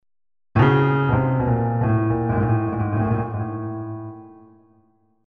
пример - задействования сразу двух струн, и передача сигнала с общего звукоснимателя на pin 5. Алгоритм захватывает частоту то одной струны то другой.